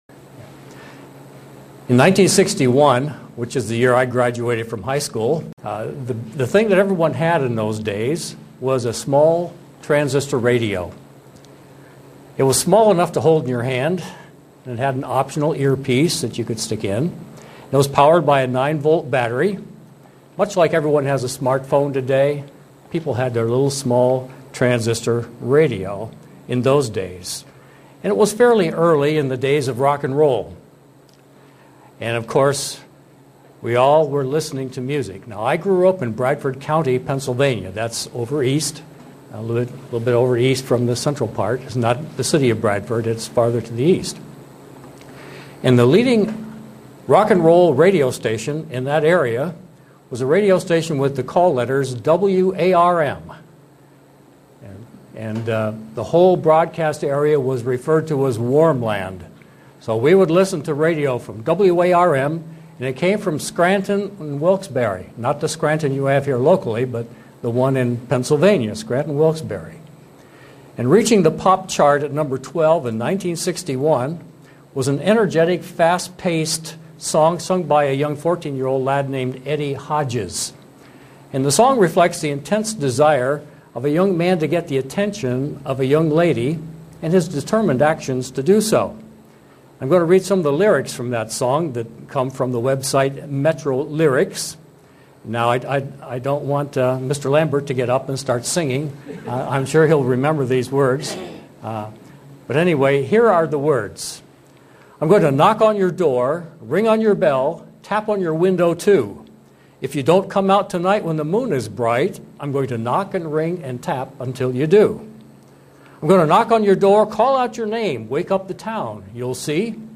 But what, when and how to is not simple. sermon Studying the bible?